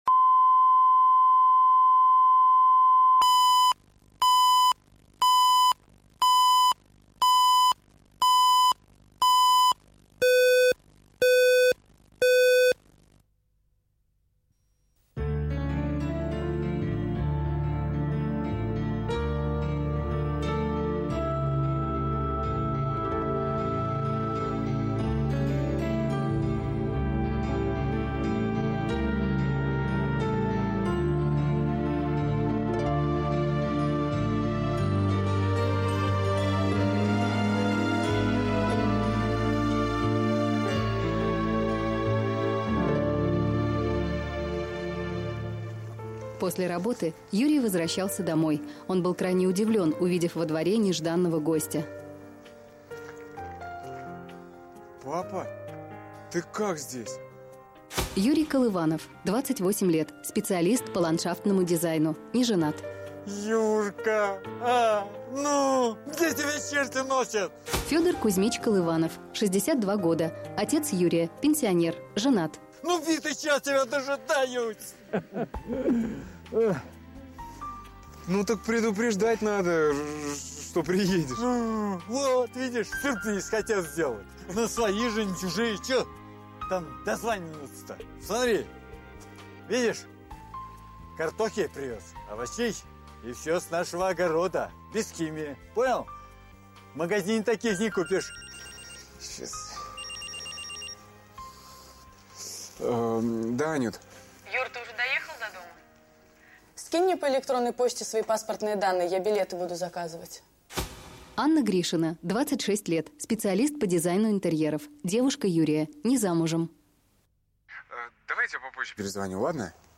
Аудиокнига С приездом, папа | Библиотека аудиокниг